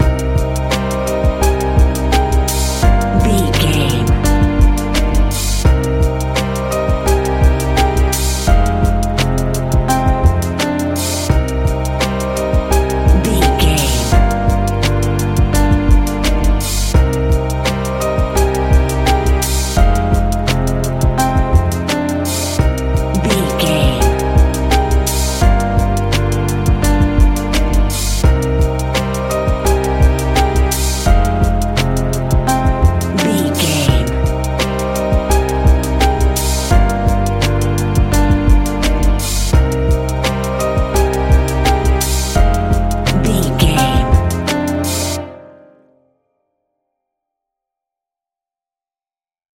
Ionian/Major
C♭
chilled
laid back
Lounge
sparse
new age
chilled electronica
ambient
atmospheric
morphing